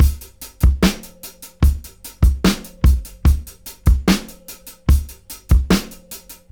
73-DRY-04.wav